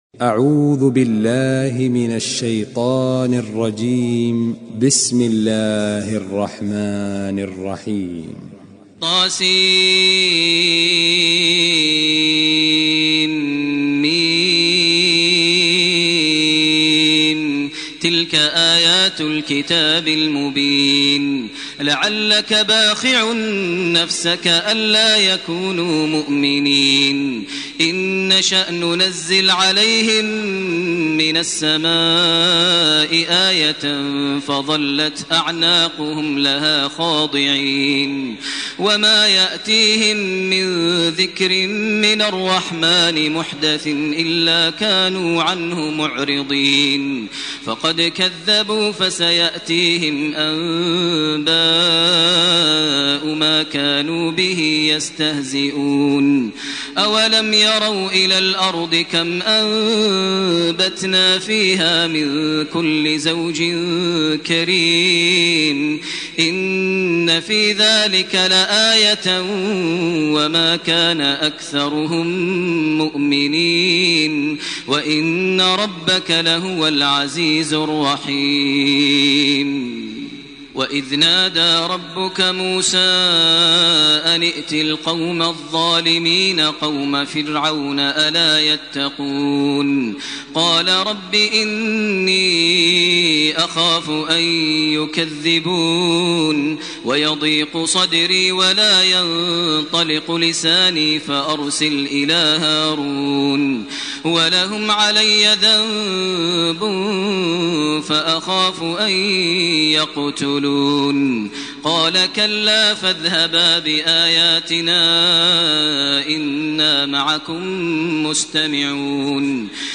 سورة الشعراء 1- 120 > تراويح ١٤٢٨ > التراويح - تلاوات ماهر المعيقلي